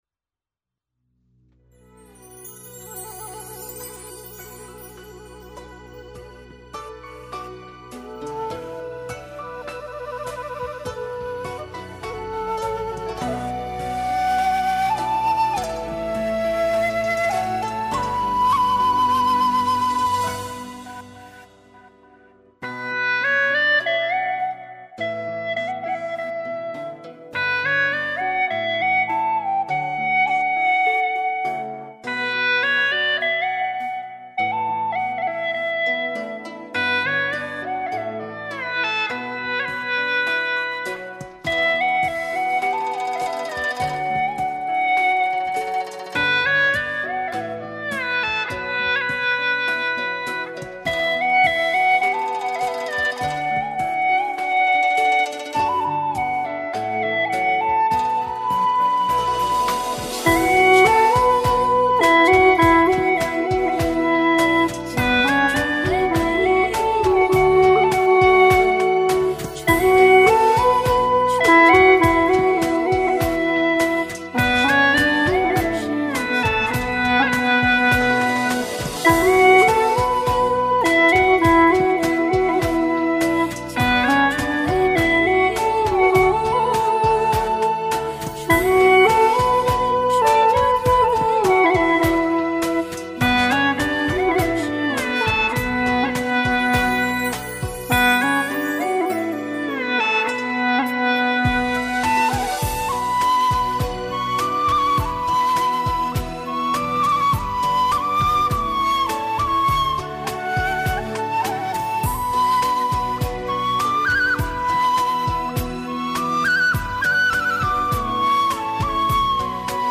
调式 : D 曲类 : 古风
【大小D调】 我要评论